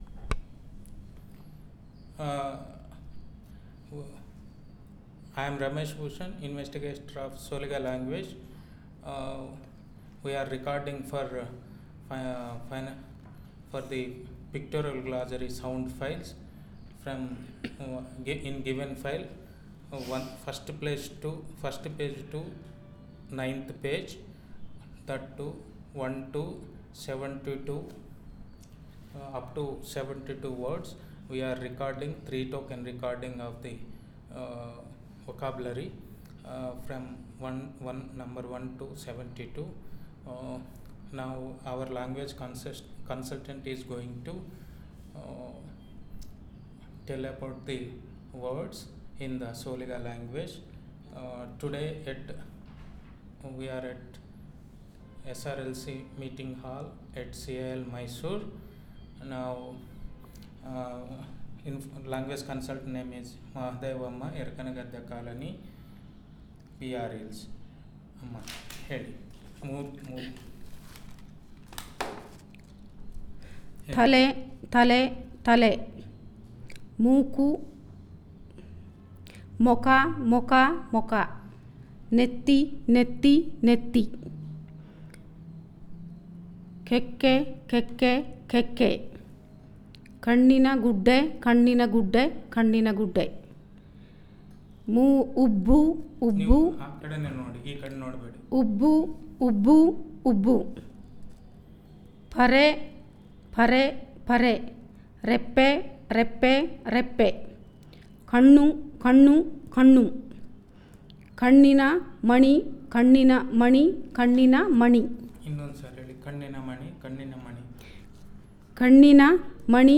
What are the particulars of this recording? NotesThis is an elicitation of wordlist on human body parts and related by using pictures in pictorial glossary 1-72 items (Pages 1-9).